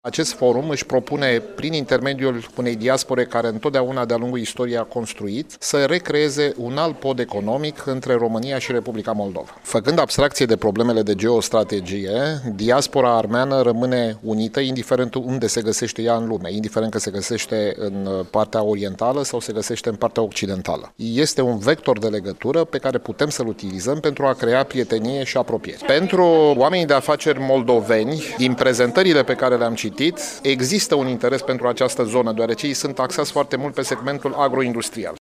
Astăzi, la Iaşi, s-a desfăşurat primul Forum Economic al Oamenilor de afaceri armeni din România şi Republica Moldova.